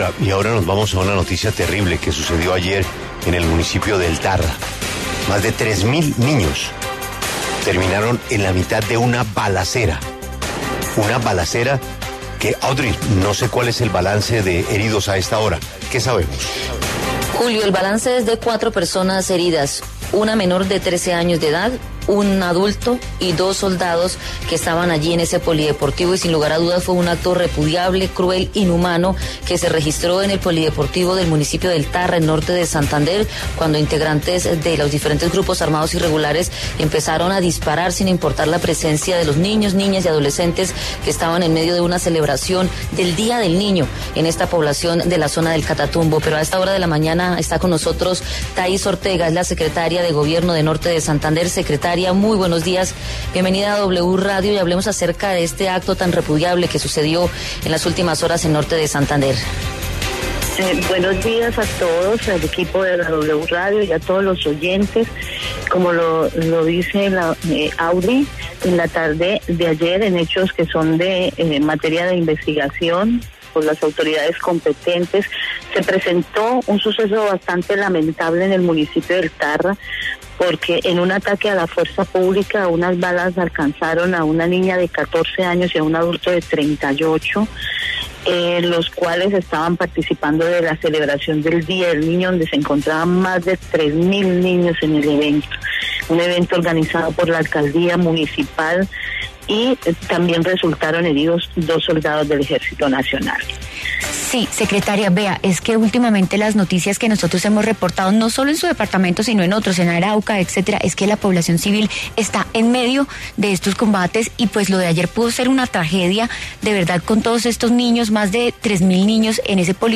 Taiz Ortega, secretaria de Gobierno de Norte de Santander, se pronunció en La W sobre el ataque armado en El Tarra que se produjo durante la celebración del Día del Niño.